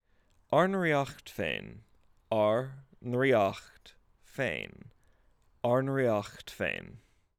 Ár nDraíocht Féin spoken What is ADF?
adf-spoken.wav